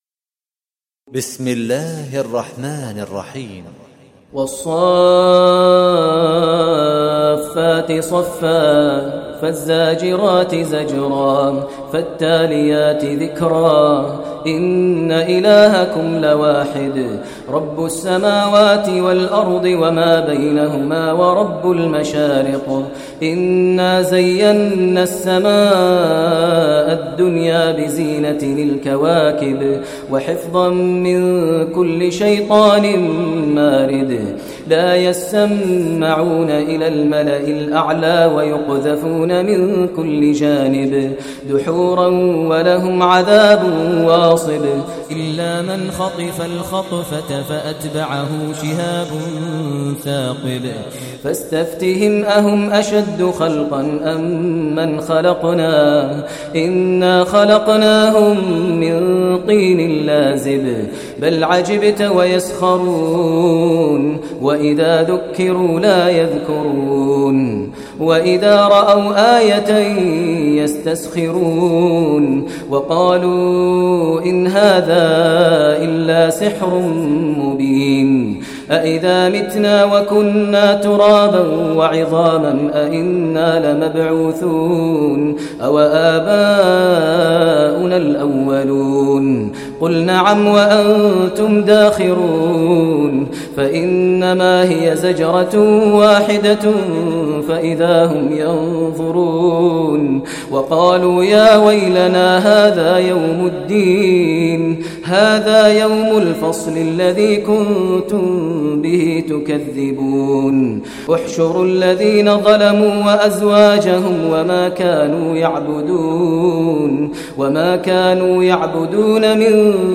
Surah Saffat Recitation by Sheikh Maher al Mueaqly
Surah As Saffat, listen online mp3 tilawat / recitation in Arabic in the voice of Imam e Kaaba Sheikh Maher al Mueaqly.